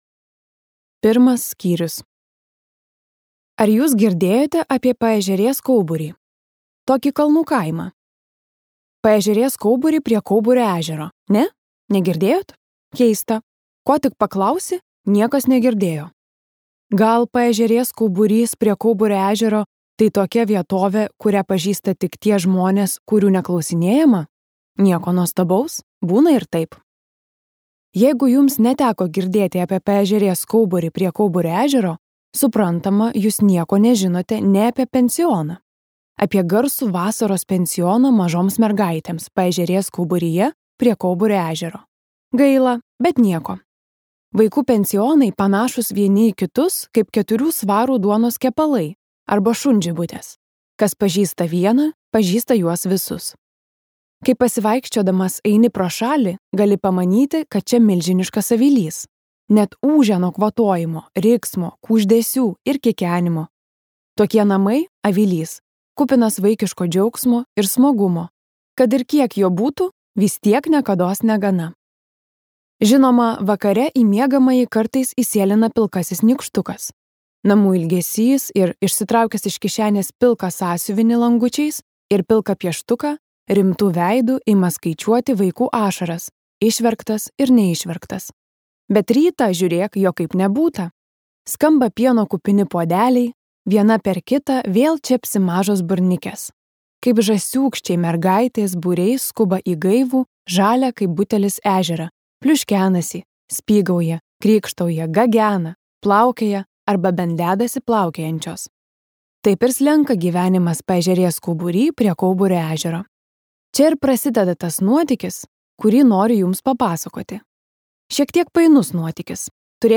Dvynukės | Audioknygos | baltos lankos